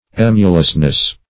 Meaning of emulousness. emulousness synonyms, pronunciation, spelling and more from Free Dictionary.
Emulousness \Em"u*lous*ness\, n.